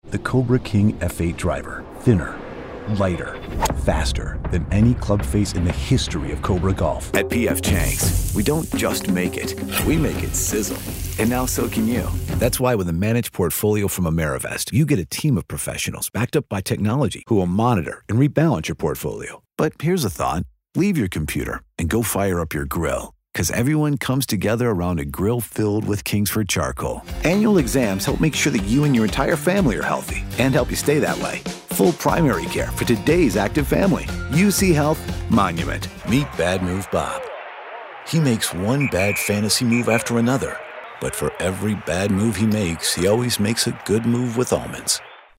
This is a warm, trustworthy, "guy next door" style and a clearly-enunciated narrator voice which ensures reliable communication and interpretation of the message within your voice cast....
English (North American) Adult (30-50) | Older Sound (50+)